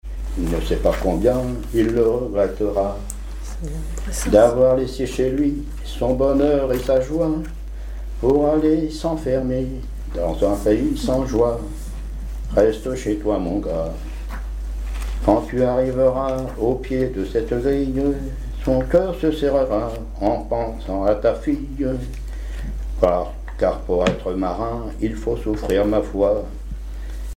Genre strophique
Chansons et commentaires
Pièce musicale inédite